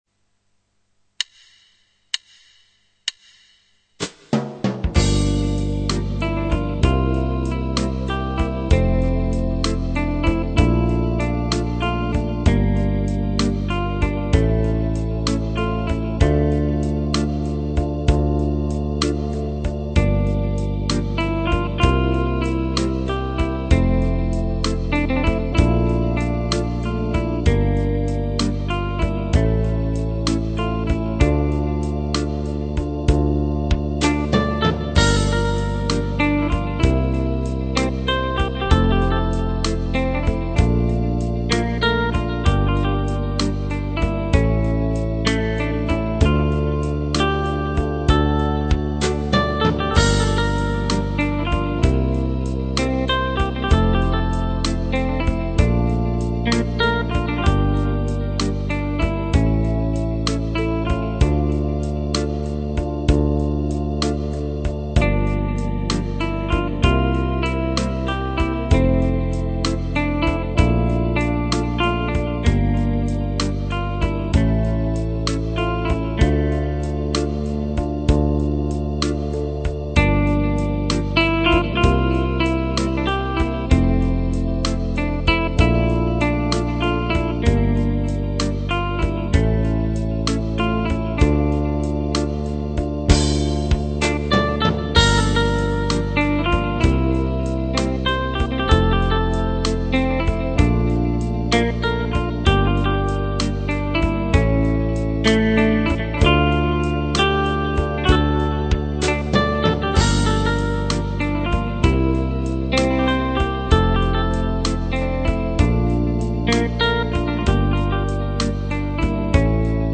аранжировка в стиле 70-х
СПАСИБО, хорошая мелодия, запоминающаяся...!
аранжировка - точнее - окончание 60-х...